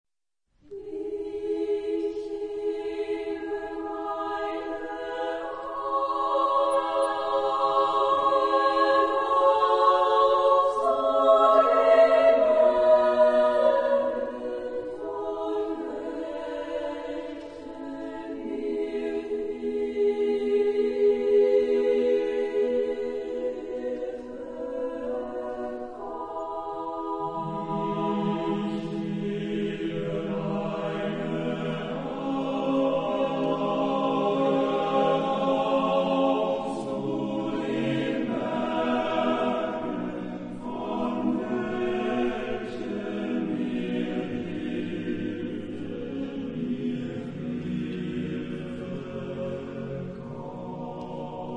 Genre-Style-Form: Motet ; Sacred ; Romantic
Type of Choir: SSAATTBB  (8 mixed voices )
Tonality: E major
Discographic ref. : Internationaler Kammerchor Wettbewerb Marktoberdorf 2007
Consultable under : Romantique Sacré Acappella